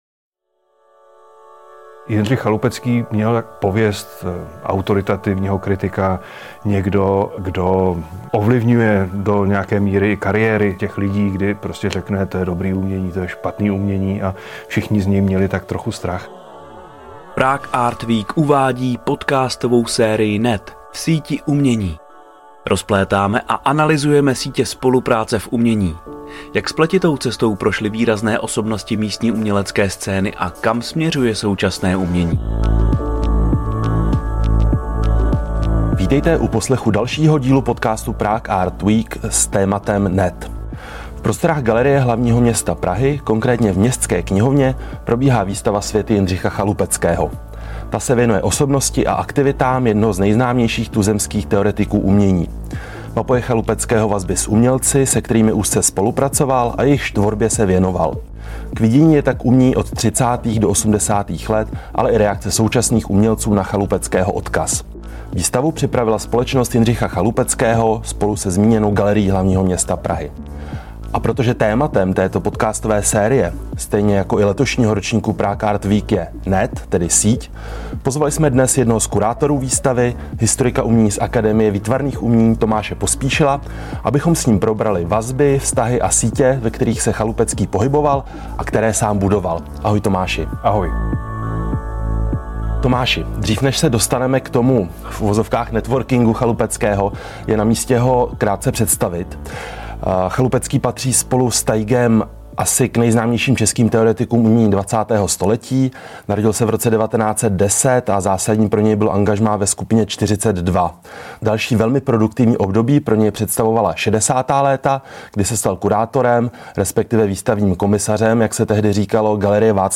V konverzační podcastové sérii NET rozplétáme a analyzujeme sítě spolupráce v umění.